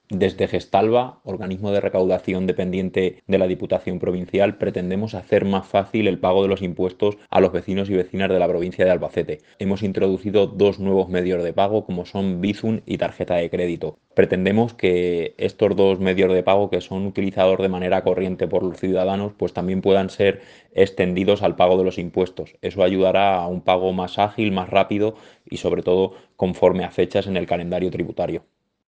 Audio de Fran Valera, vicepresidente de la Diputación de Albacete, sobre nuevas forma de pago en Gestalba
Corte-de-Fran-Valera-sobre-nuevas-forma-de-pago-en-Gestalba.mp3